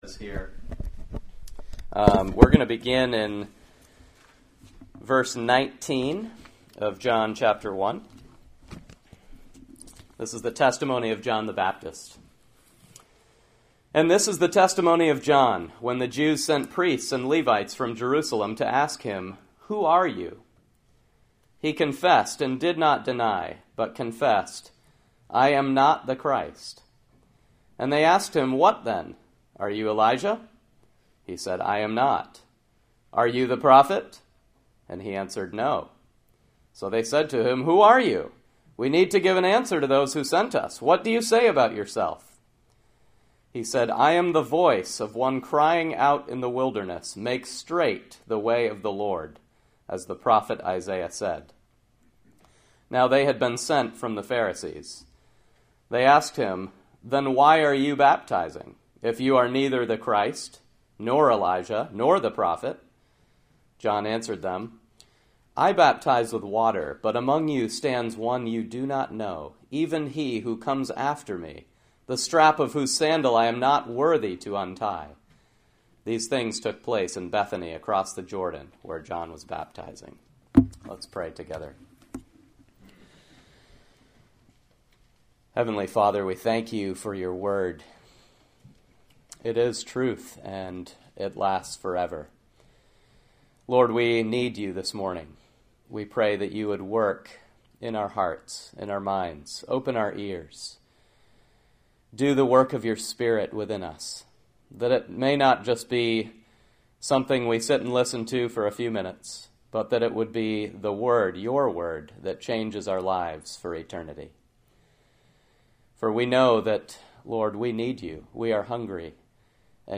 May 20, 2016 Guest Speaker Sermons series Presbytery Service Save/Download this sermon John 1:19-28 Other sermons from John The Testimony of John the Baptist 19 And this is the testimony of […]